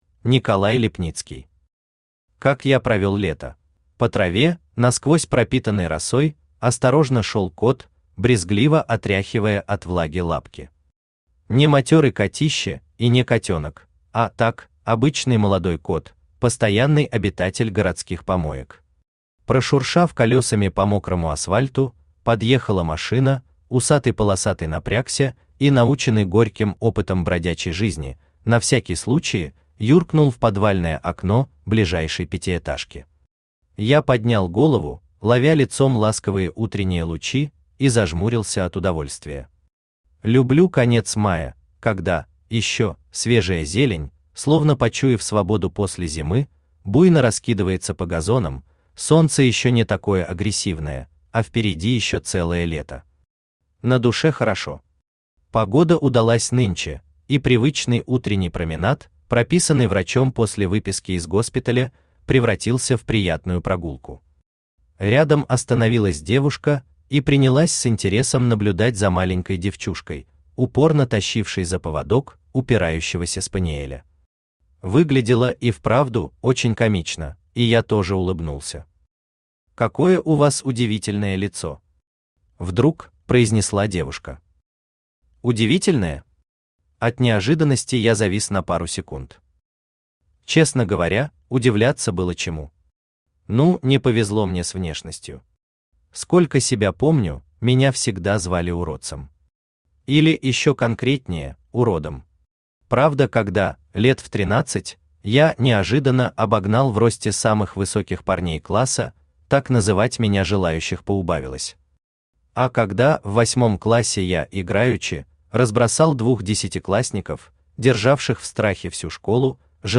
Читает: Авточтец ЛитРес
Аудиокнига «Как я провёл лето».